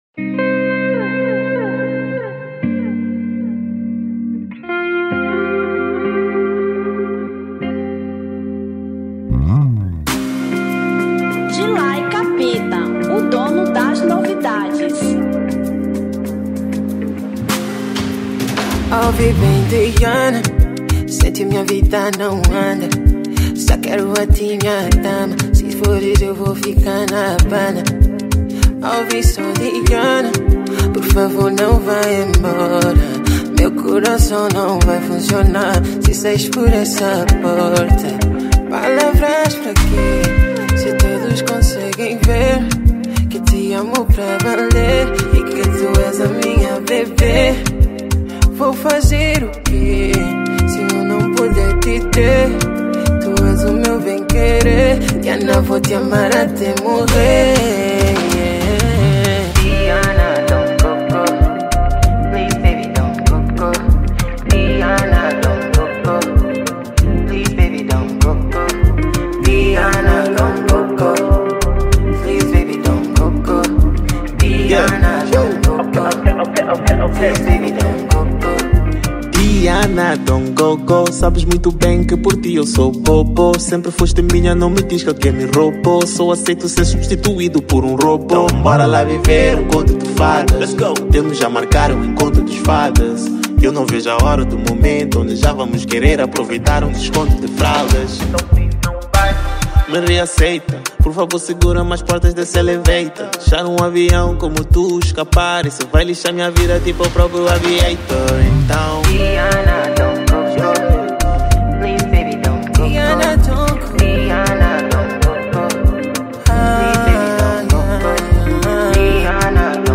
Dance Hall 2025